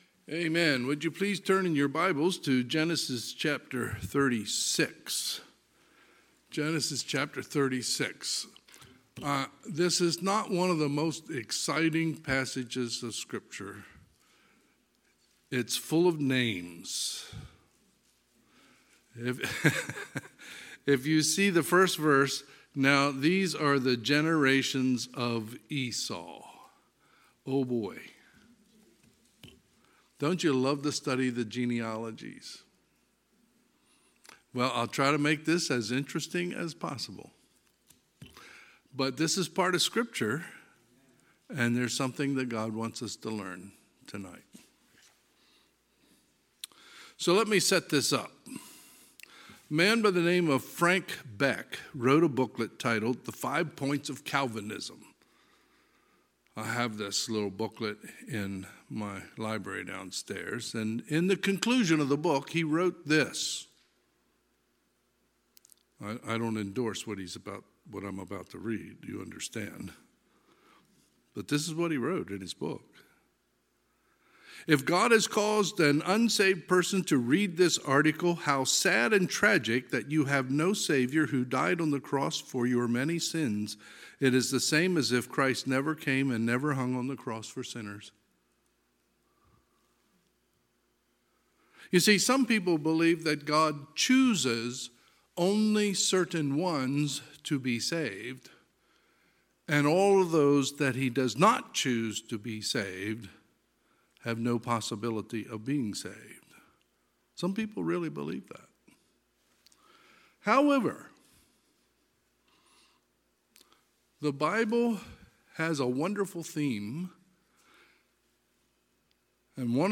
Sunday, January 1, 2023 – Sunday PM